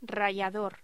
Locución: Rallador
Sonidos: Voz humana